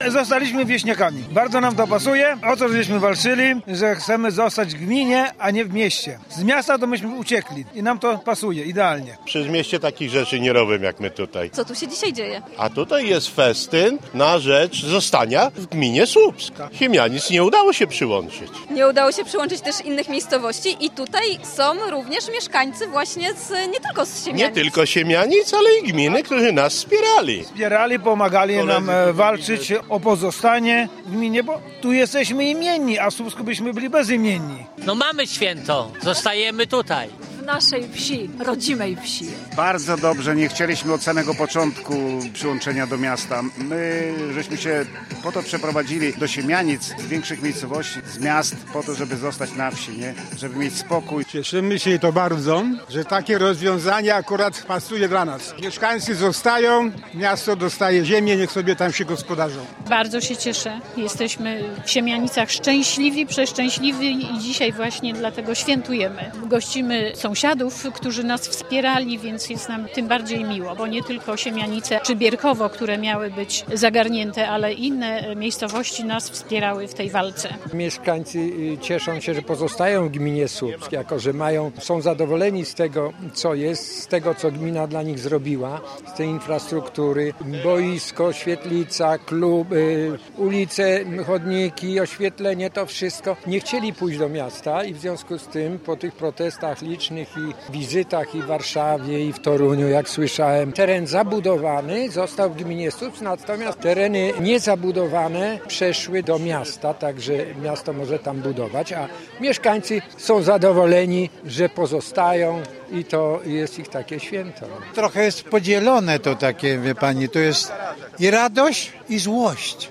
– Wywalczyliśmy to sami, protestując i głośno mówiąc, że nie chcemy tej zmiany – mówili podczas sobotniego pikniku siemianiczanie.